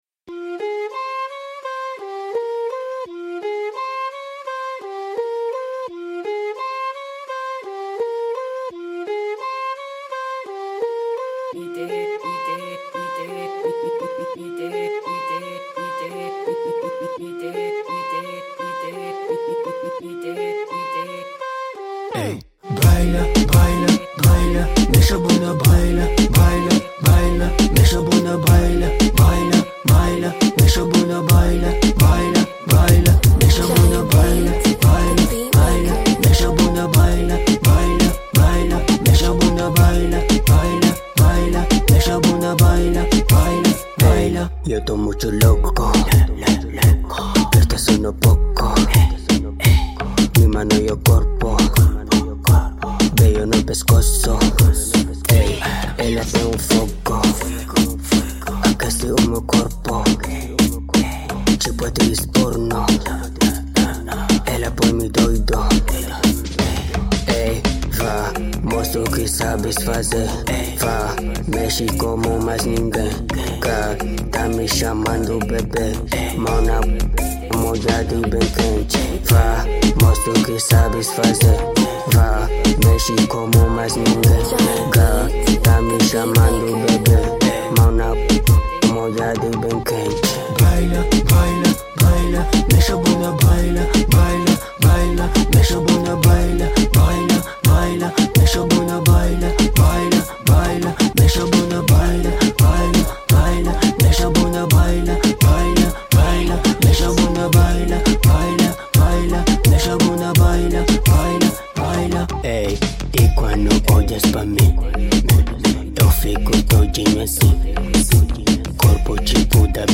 Genero: Reggaeton